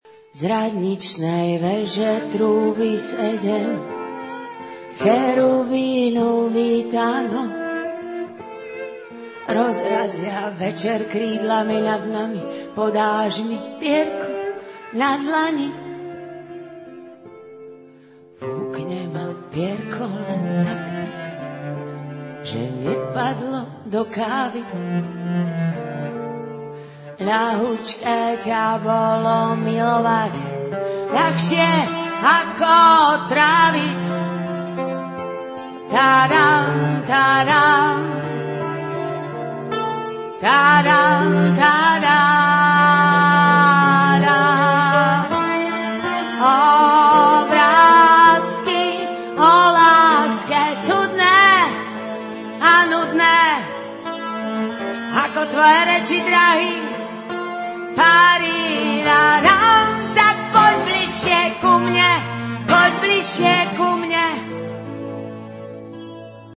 guitars
flute
violoncello
percussion